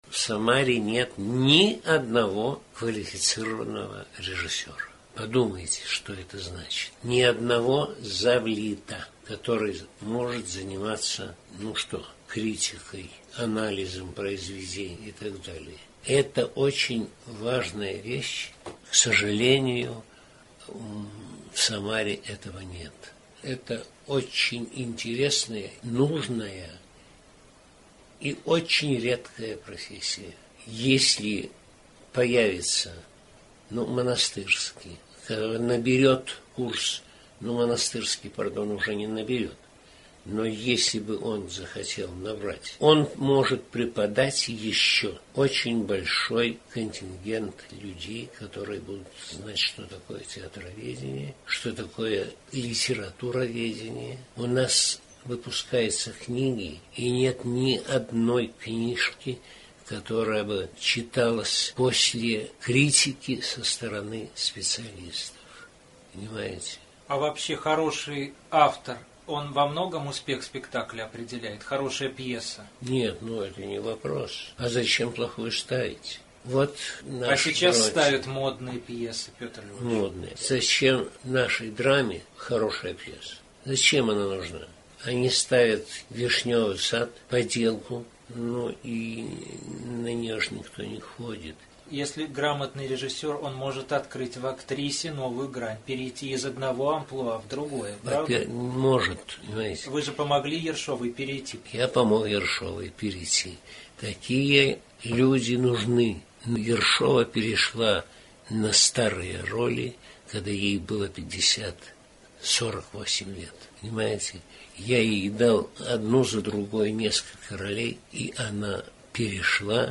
Последнее интервью